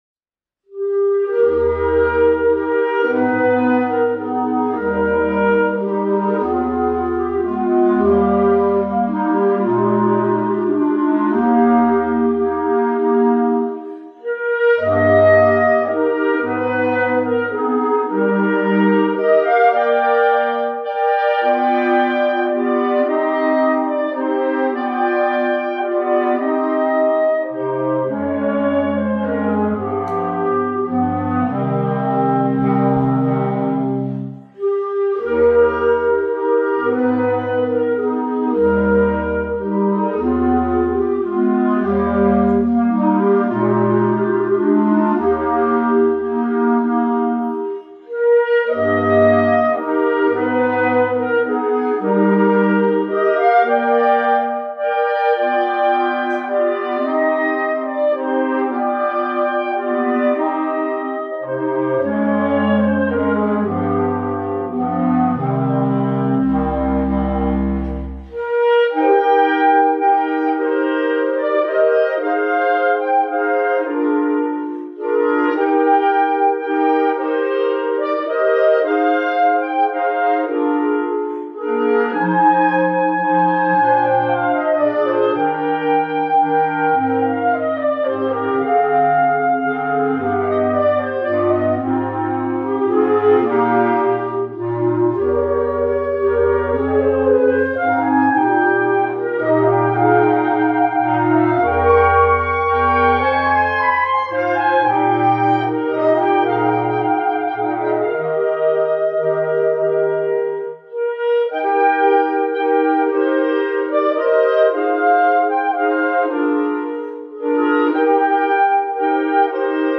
单簧管四重奏
古典
这首乐曲是古老的八六拍舒缓舞曲。